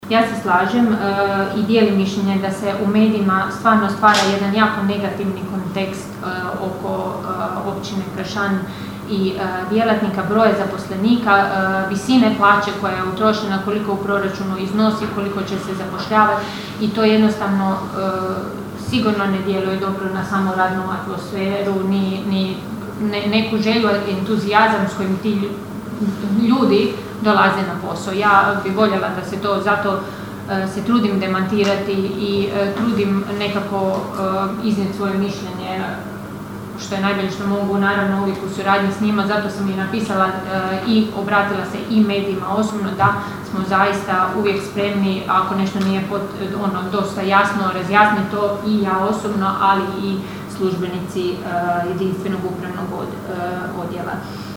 Prijedlog Odluke o II. izmjenama i dopunama Odluke o unutarnjem ustrojstvu i djelokrugu općinske uprave izazvao je žustru raspravu na ovotjednoj sjednici Općinskog vijeća Kršana.
Načelnica Ana Vuksan: (